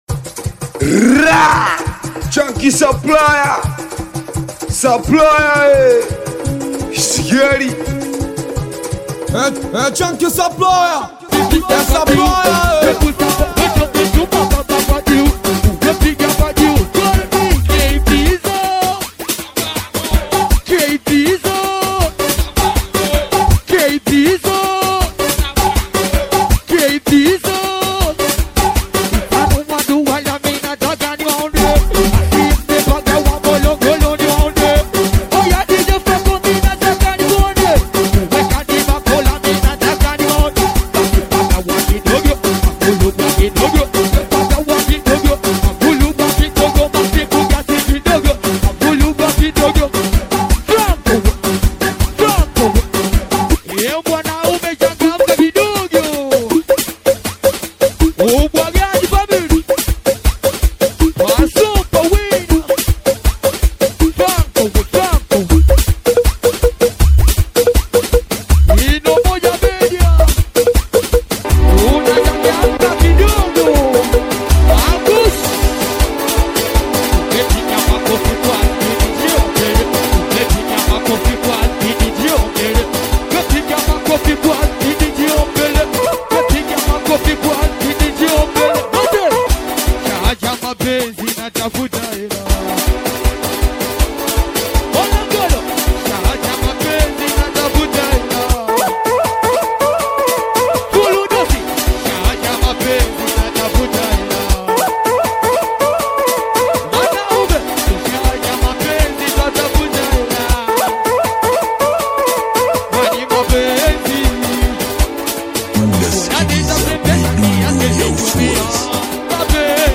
SHOW LIVE AUDIO